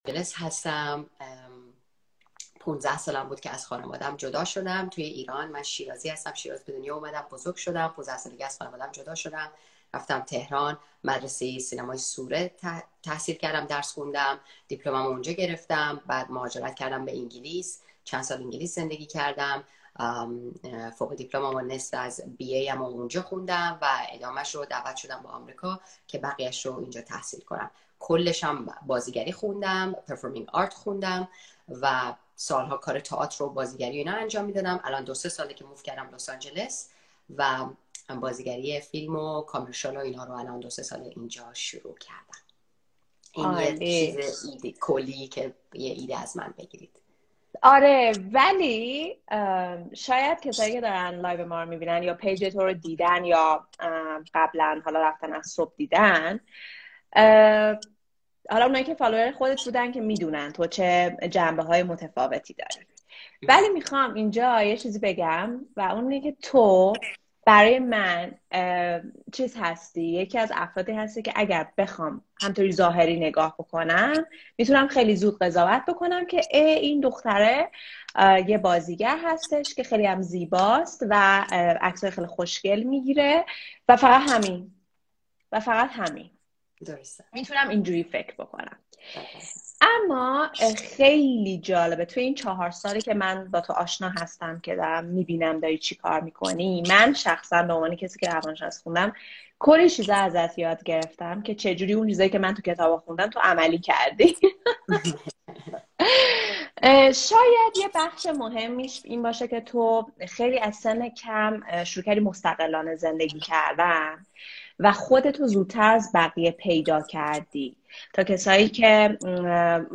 برنامه لايو